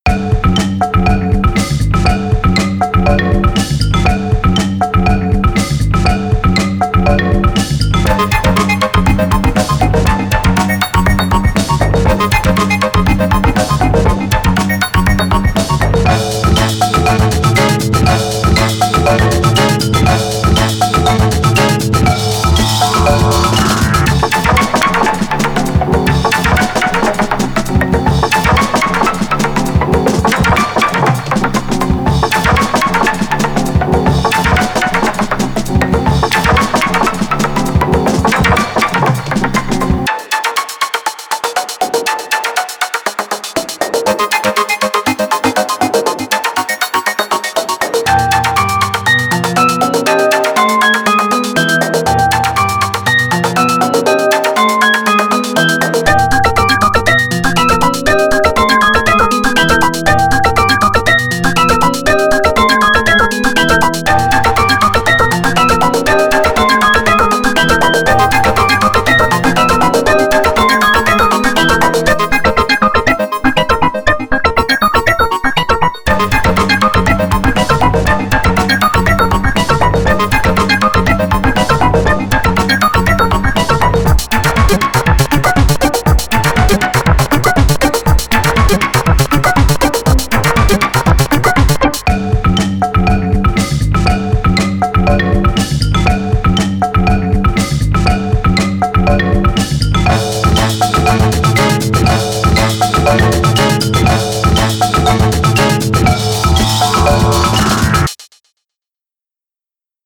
Tracktion's Collective synth/sampler;
Beats